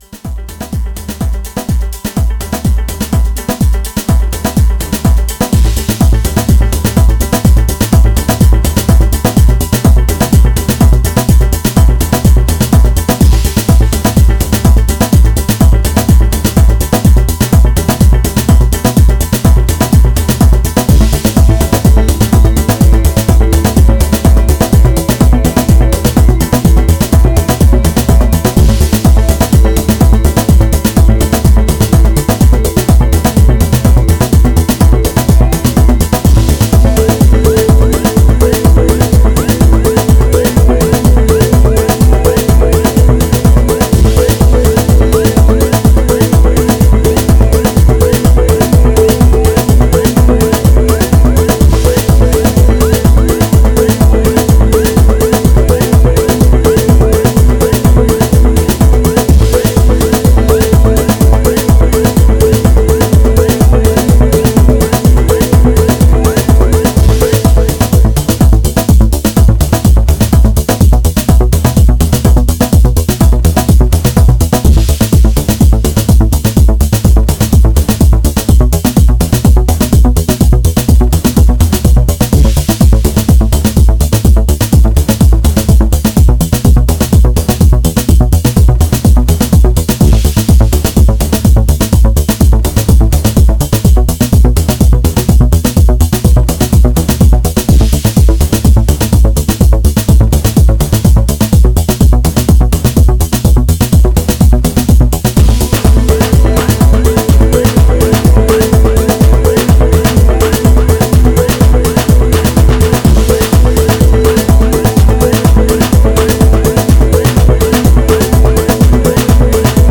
Excellent warm Chicago house tools by this '90s veteran.